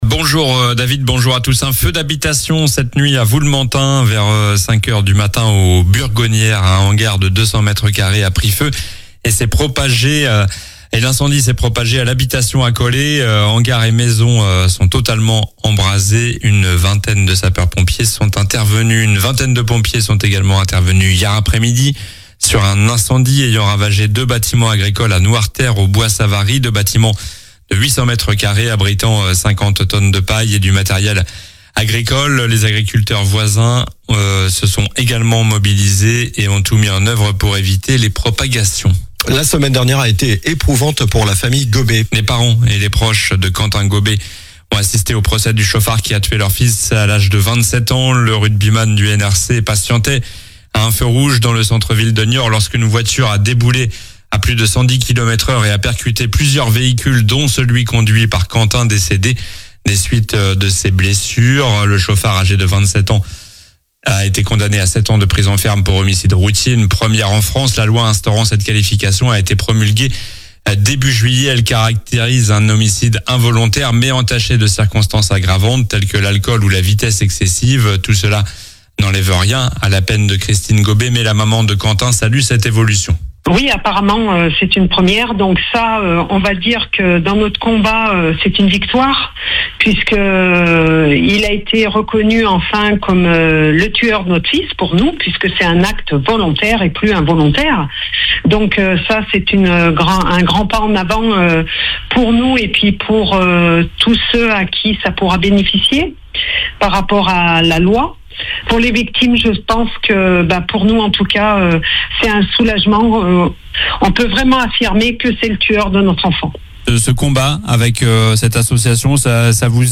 COLLINES LA RADIO : Réécoutez les flash infos et les différentes chroniques de votre radio⬦
Journal du lundi 21 juillet (matin)